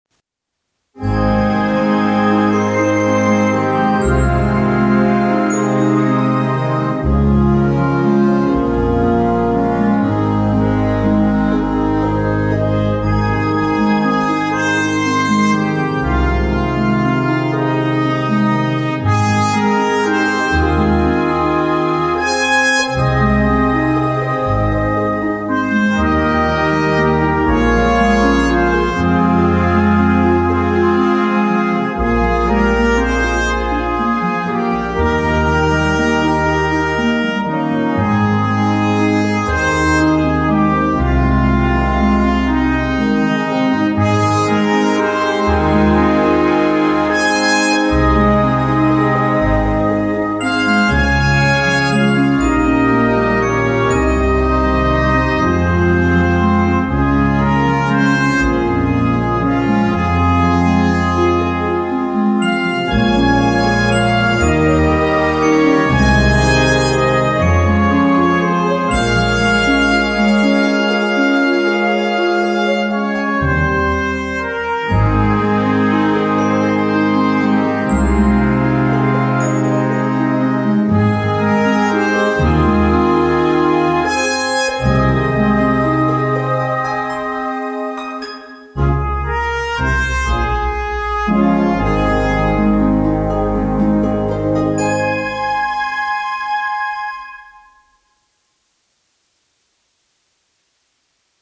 for Solo Bb Cornet and Concert Band